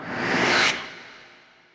SFX BlackTiger.wav